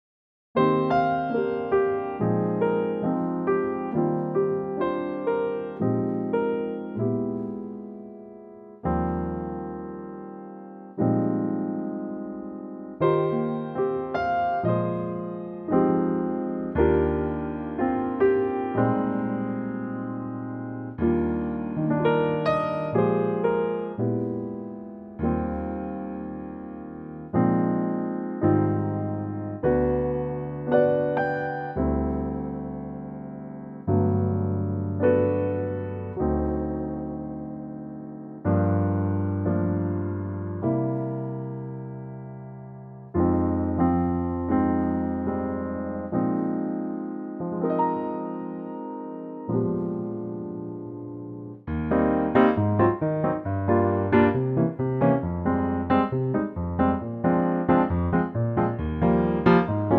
Eb Piano Latin
key - Eb - vocal range - C to F
Wonderful piano only arrangement
that goes into a beguine latin feel.